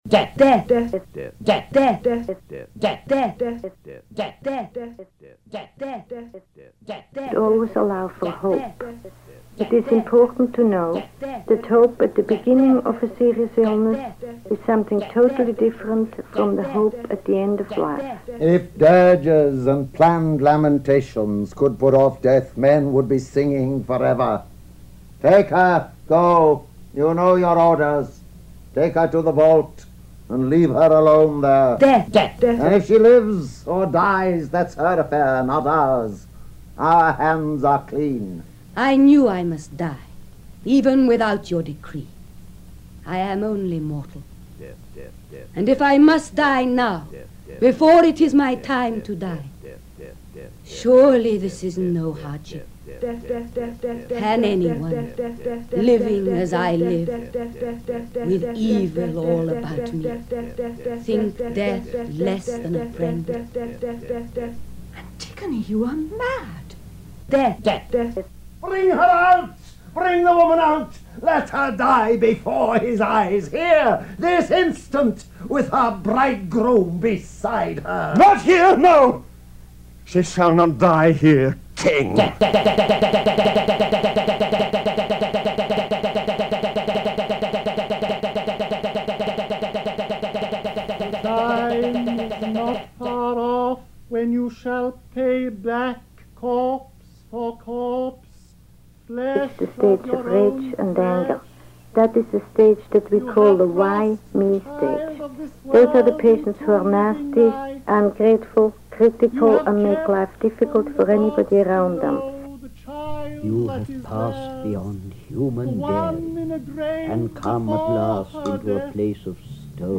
There is a lot of hiss on the tapes, which carried over to my piece.